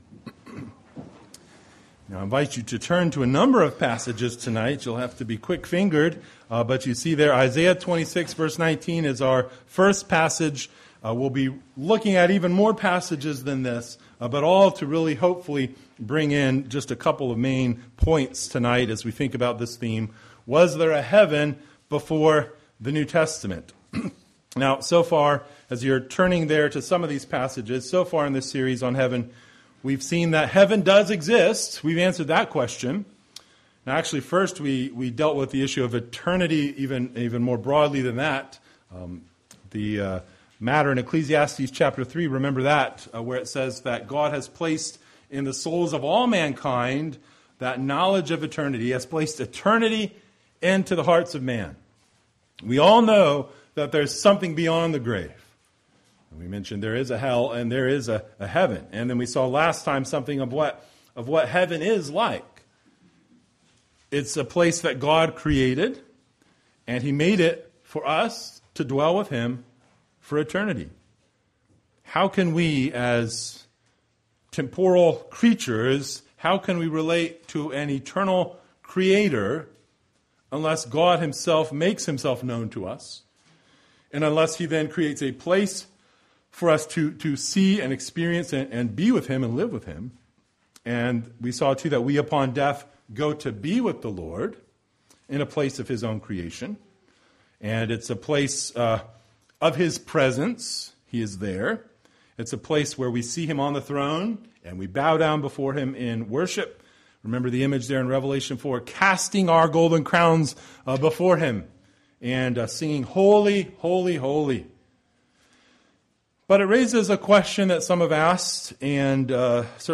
Passage: Isaiah 26:19, Psalm 16:11, Psalm 17:15, I John 3:2 Service Type: Sunday Evening Related Topics